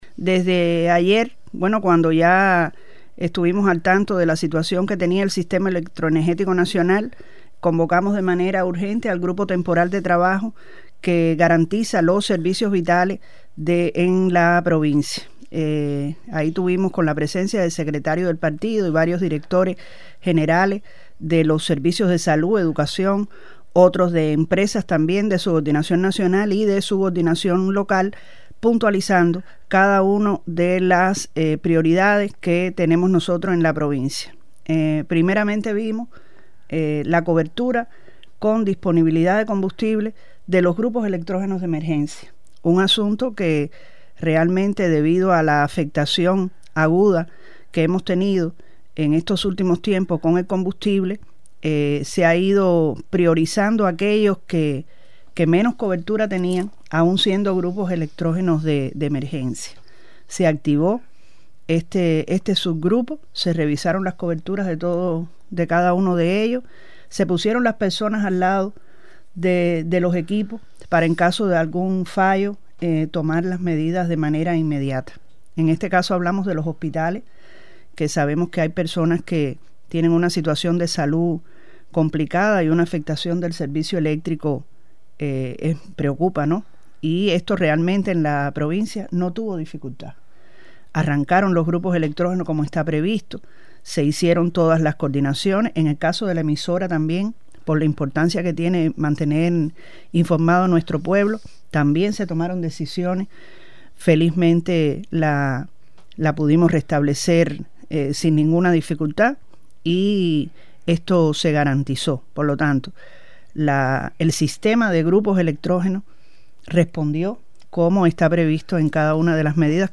Yolexis Rodríguez Armada, Gobernadora Provincial, y representantes de varios organismos intervinieron este jueves en la revista A esta Hora, para ofrecer detalles de las medidas adoptadas para enfrentar la desconexión del Sistema Electronergético Nacional.
Yolexis Rodríguez Armada, Gobernadora Provincial 👇
YOLEXIS-GOBERNADORA-CIENFUEGOS-.mp3